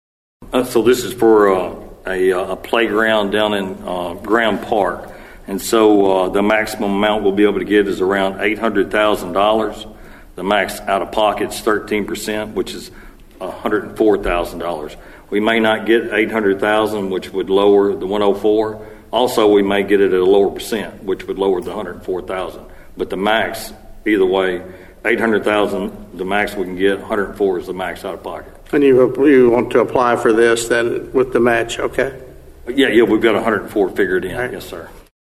City Manager Johnny McTurner explained the grant request at the latest city meeting.(AUDIO)